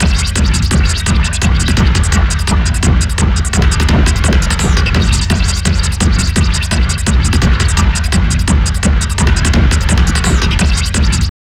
_ACID SEEMS 1.wav